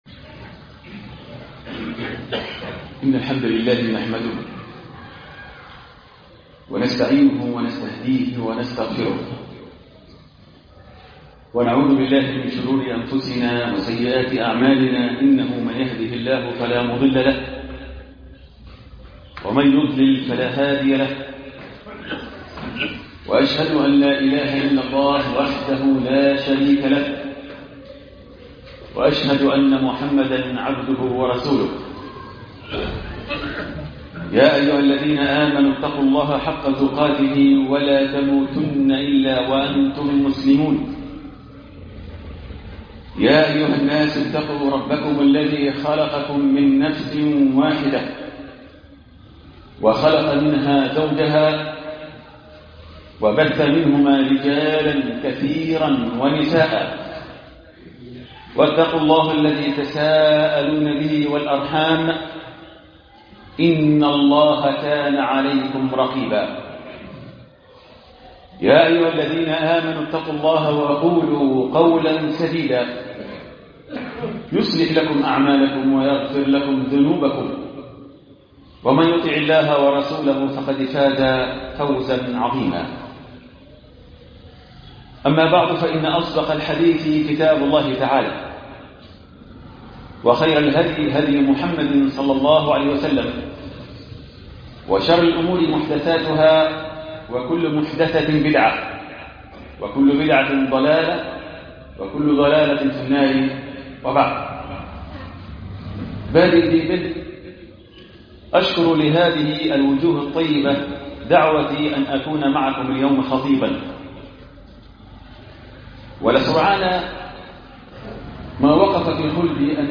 دعوة لتدبر القرآن الكريم ..خطبة الجمعة